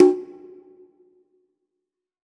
WCONGA HI.wav